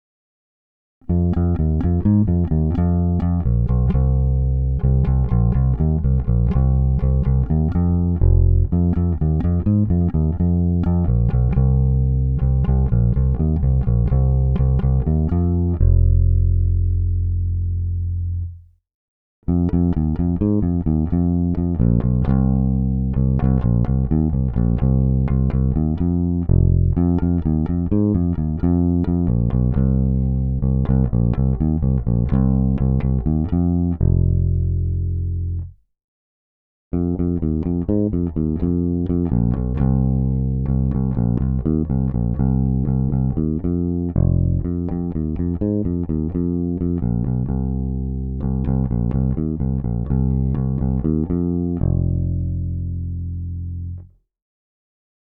Snímač je kousavější, agresívnější, a to i přes použité hlazené struny Thomastik-Infeld Jazz Flat Wound JF344 (recenze), jinak klasický precižnovský charakter se nezapře. S nimi jsem provedl nahrávku rovnou do zvukovky.